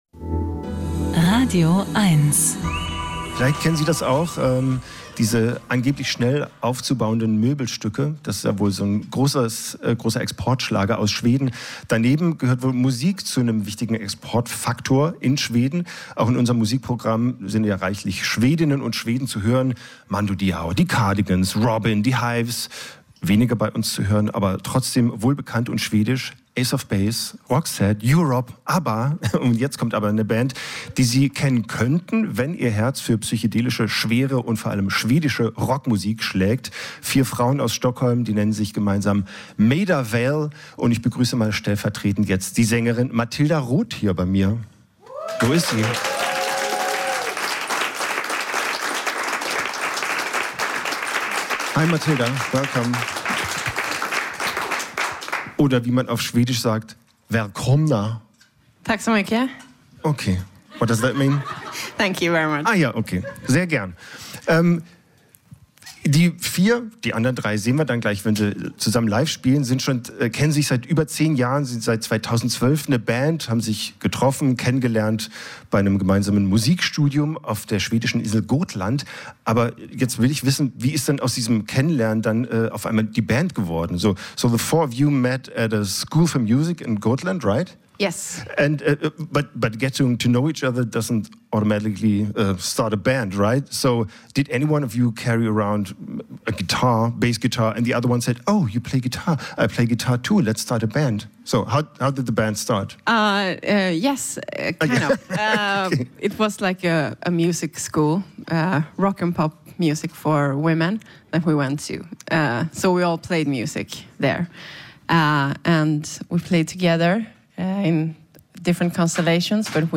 Musik-Interviews
radioeins hat sie alle! Die besten Musiker im Studio oder am Telefon gibt es hier als Podcast zum Nachhören.